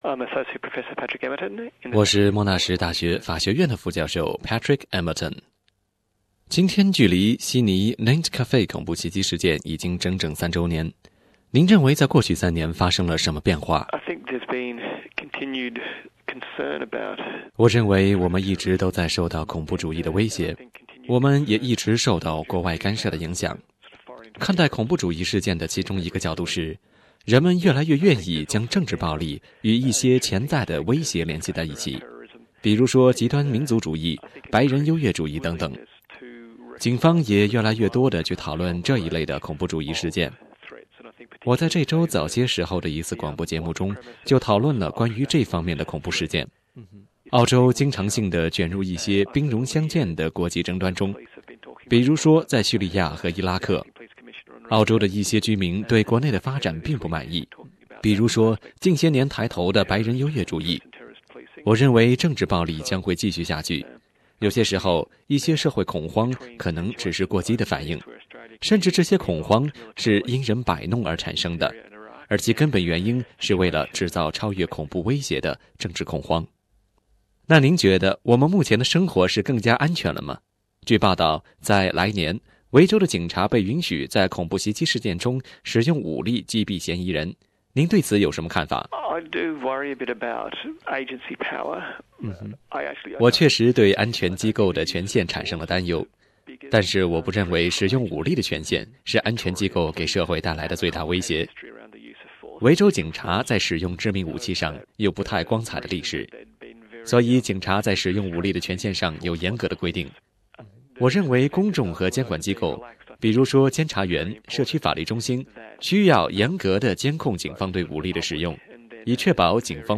（以上仅是嘉宾的个人观点，不代表本台立场。）